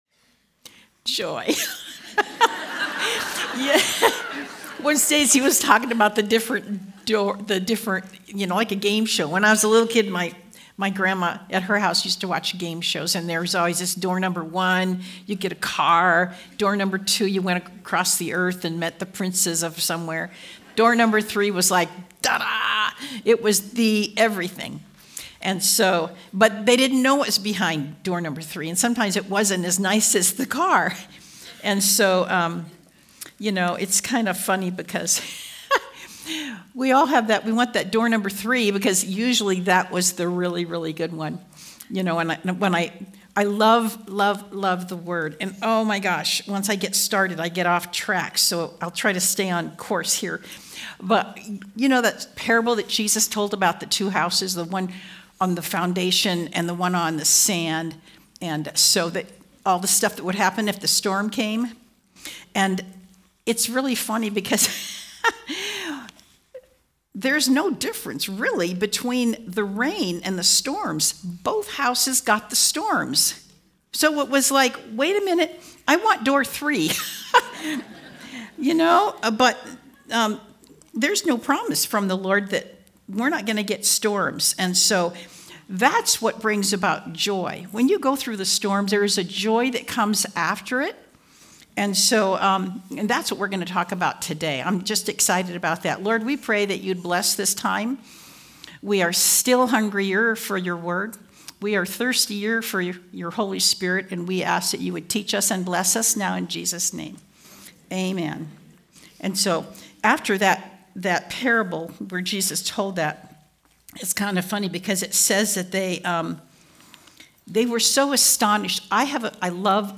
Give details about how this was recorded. Conference: Women's Gathering